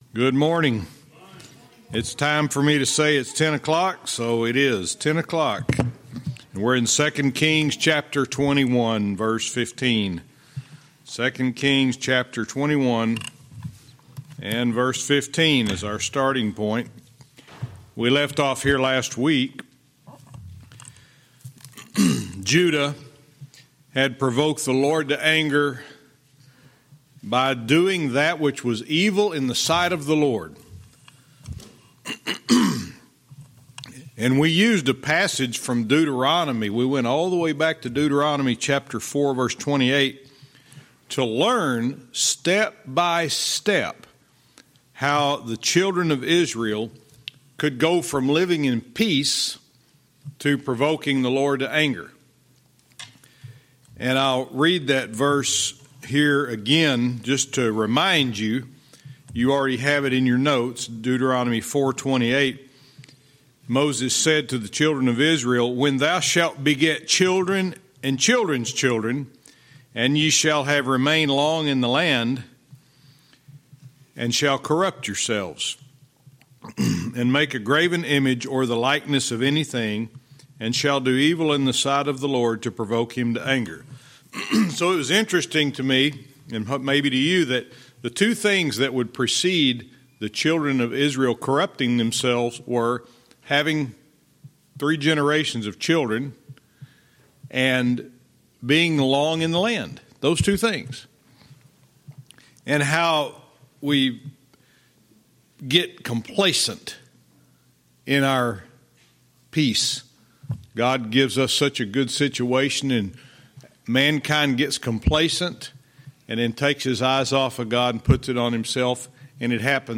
Verse by verse teaching - 2 Kings 21:15-17